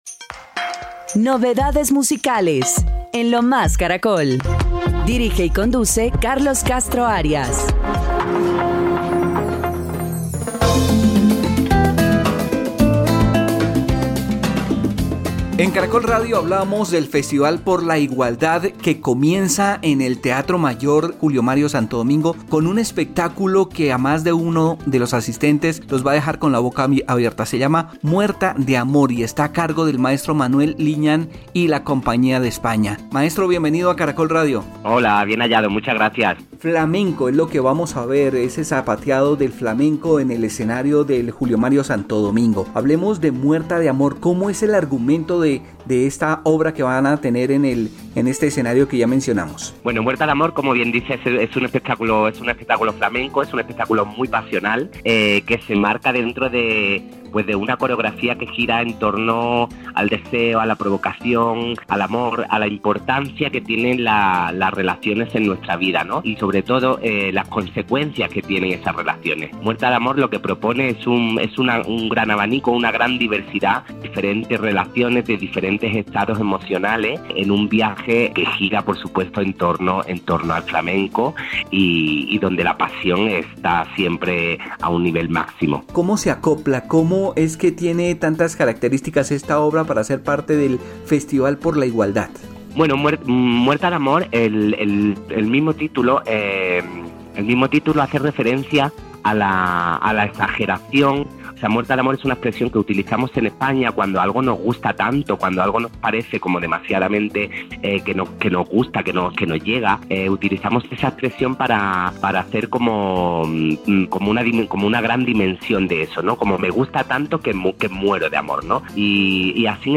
En diálogo con Lo Más Caracol, explicó que “Muerta de Amor” es una obra que gira en torno al deseo, el romance y el amor.